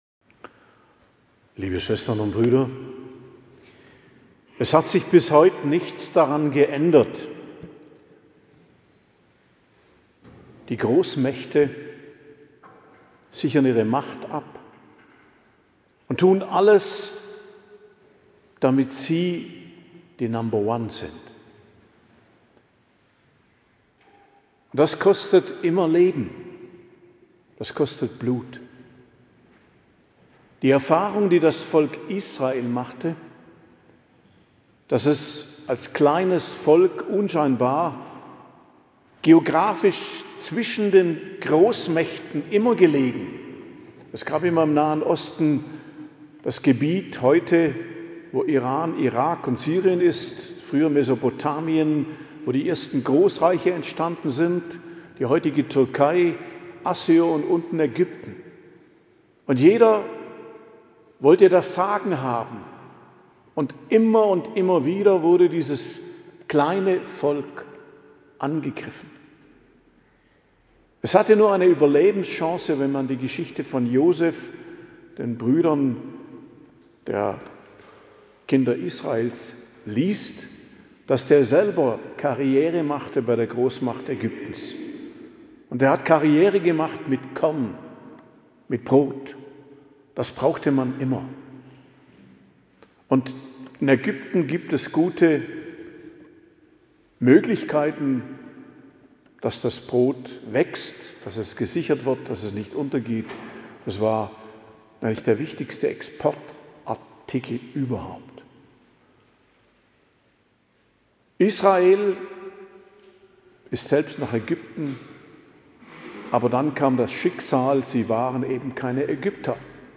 Predigt am Gründonnerstag, Messe vom letzten Abendmahl, 2.04.2026 ~ Geistliches Zentrum Kloster Heiligkreuztal Podcast